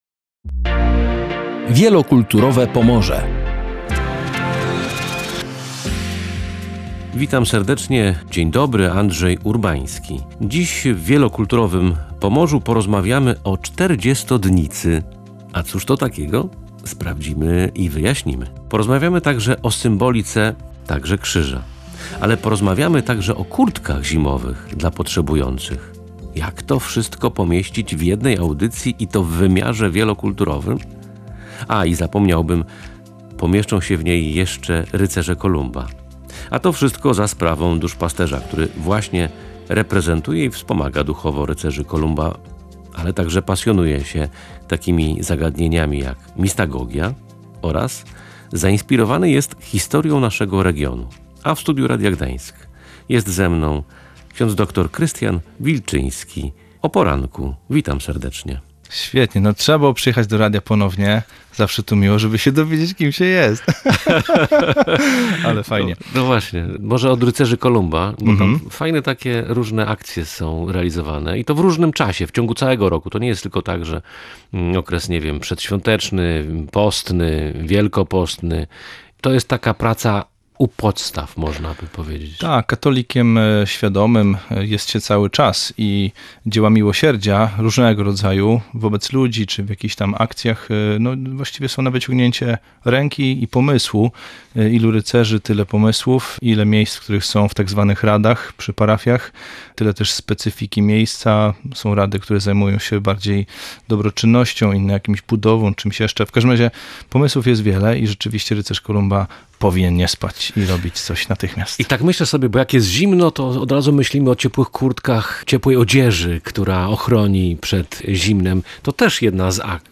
O czterdziestodnicy, rycerzach i zimowych kurtkach porozmawialiśmy w audycji „Wielokulturowe Pomorze”.